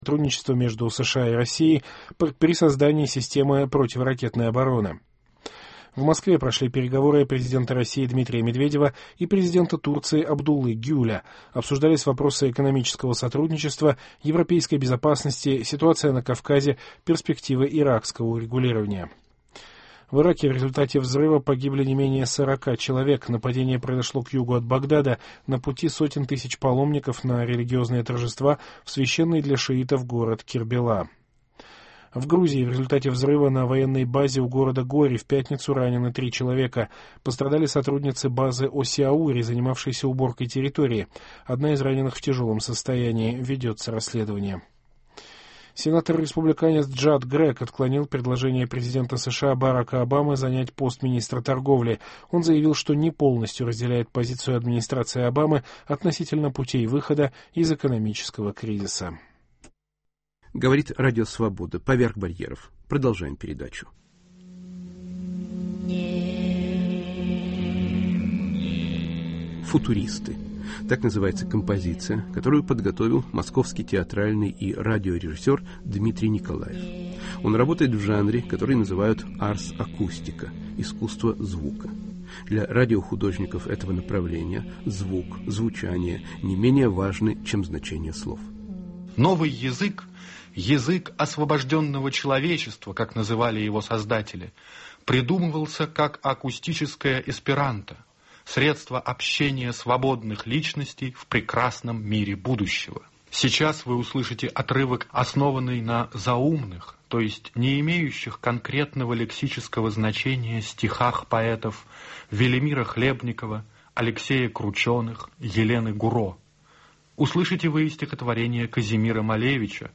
"Футуристы" -радиокомпозиция ветерана жанра "арс акустика" ("искусство звука")